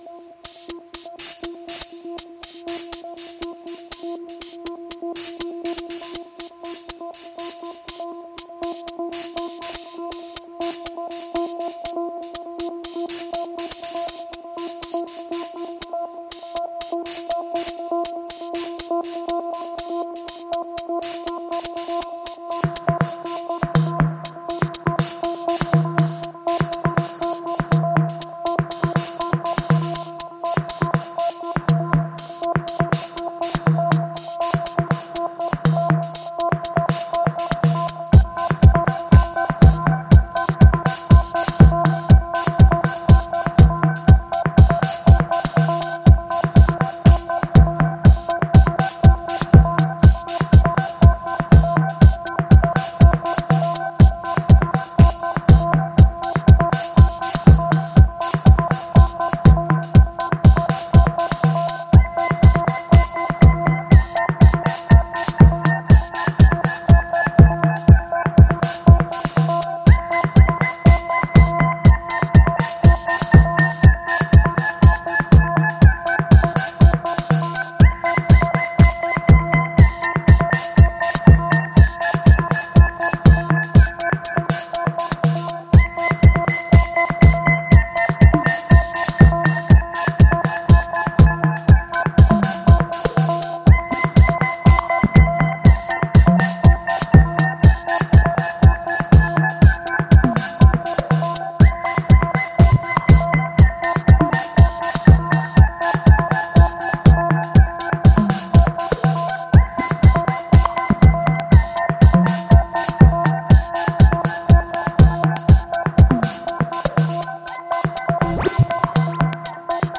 a contemplative, balmy antidote to the tension